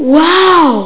1 channel
WOWWOMAN.mp3